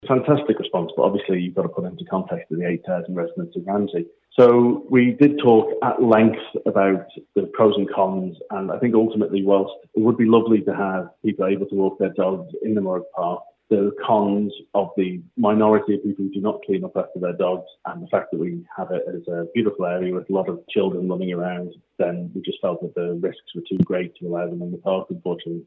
Board member Juan McGuinness said 500 people got in touch with their views: